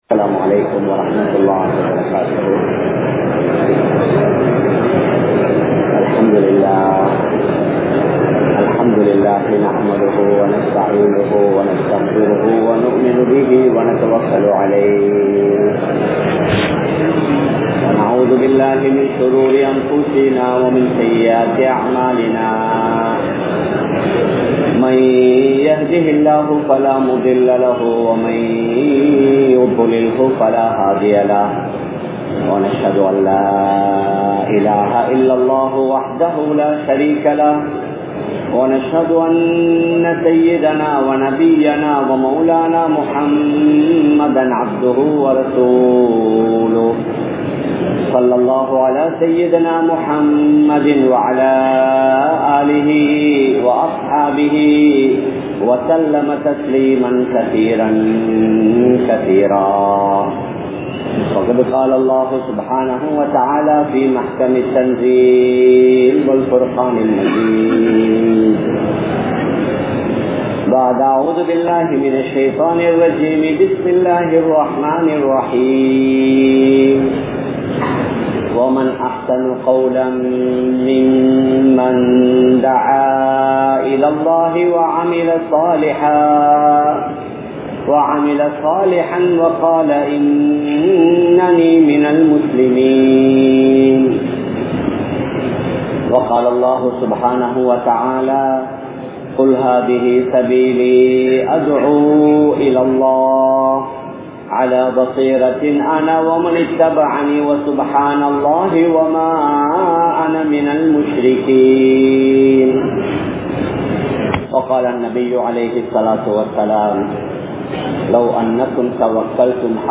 Mun Maathiriyana Islamiya Vaalifarhal (முன்மாதிரியான இஸ்லாமிய வாலிபர்கள்) | Audio Bayans | All Ceylon Muslim Youth Community | Addalaichenai